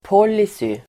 Ladda ner uttalet
policy substantiv, policy Uttal: [p'ål:isy] Böjningar: policyn Synonymer: riktlinje Definition: handlingsprogram som styr kommande beslut Exempel: formulera ett företags policy (devise a company's policy)